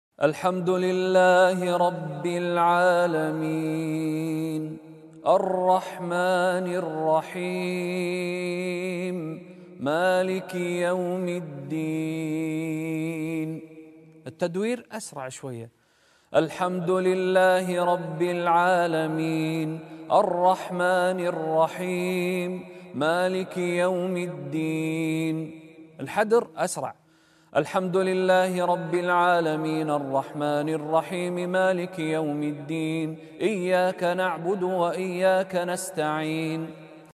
The speeds of recitation— Tarteel, sound effects free download
The speeds of recitation— Tarteel, Tadweer, Hadr.